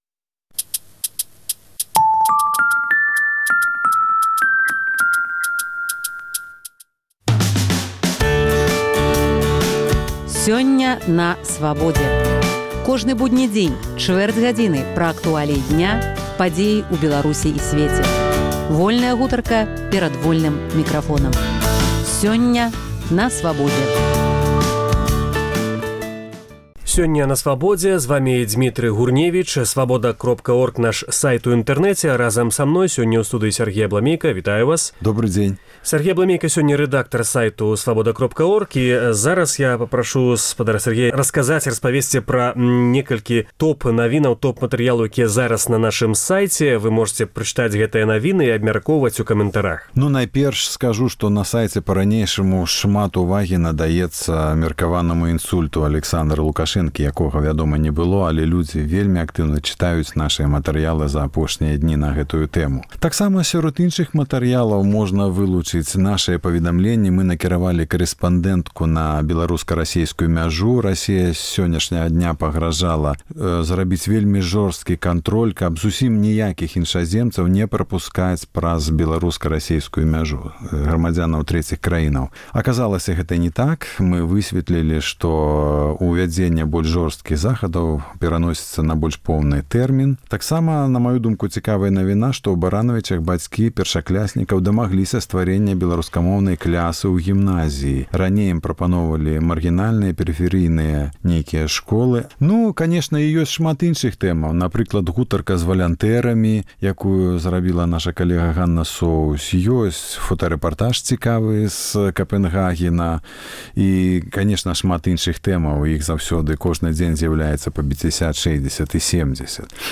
Гутарка на тэмы дня